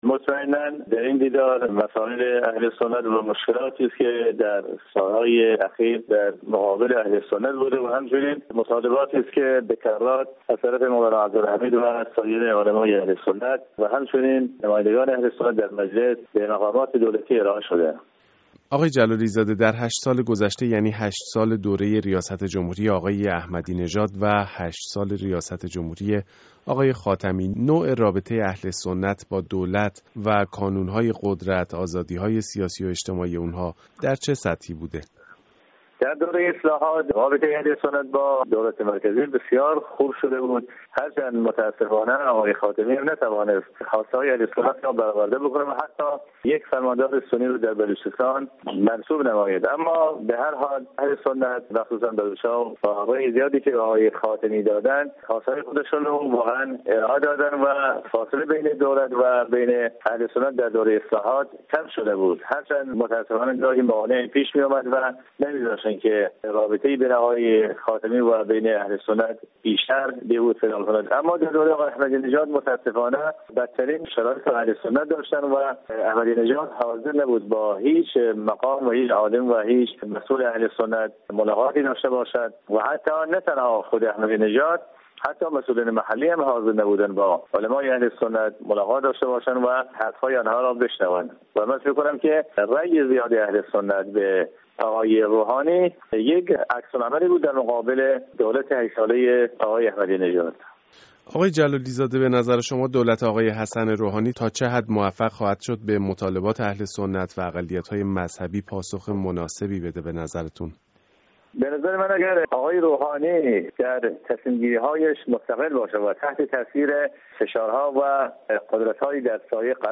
گفت و گو با جلال جلالی زاده نماینده سنندج درباره مطالبات اهل سنت از حسن روحانی